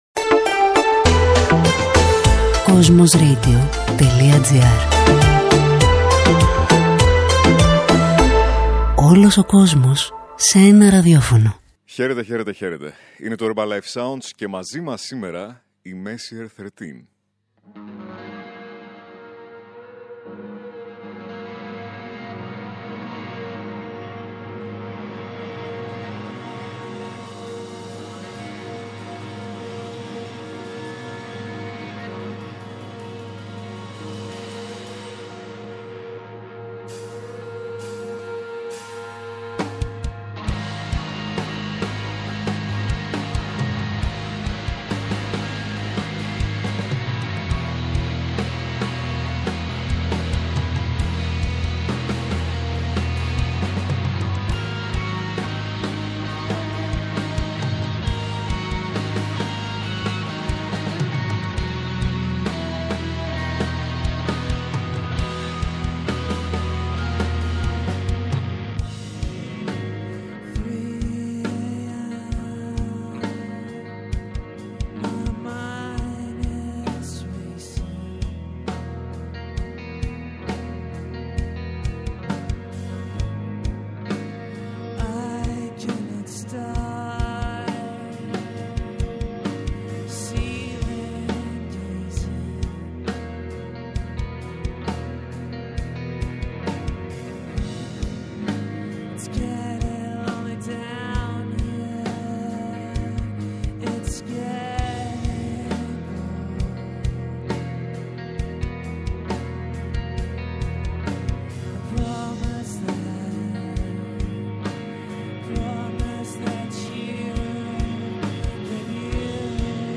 σε ένα ξεχωριστό live session